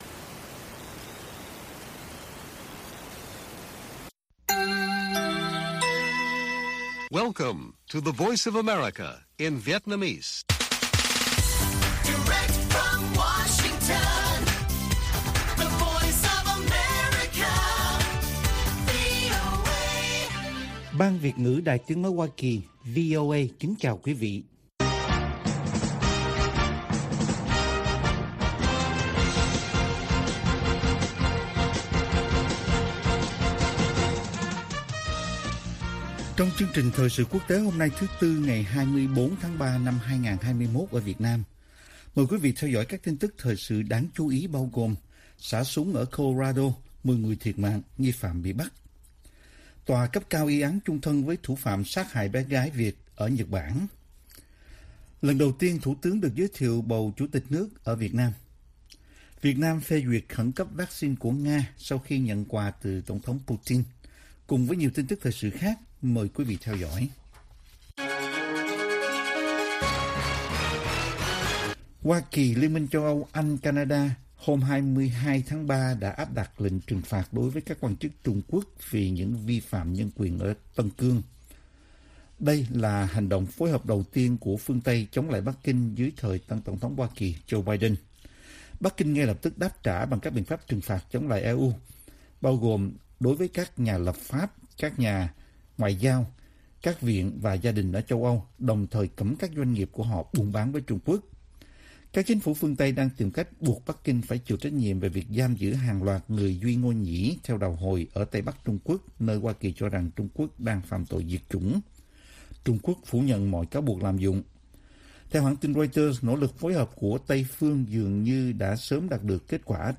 Bản tin VOA ngày 24/3/2021